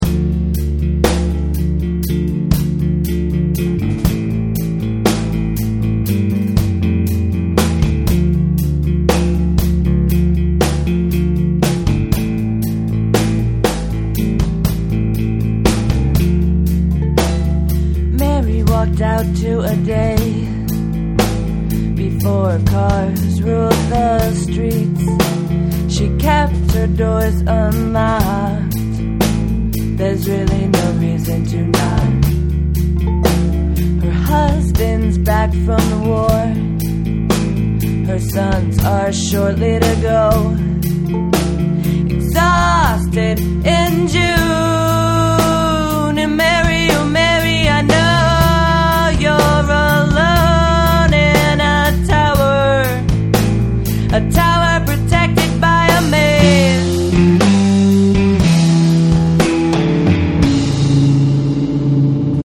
recorded in my living room using a similar setup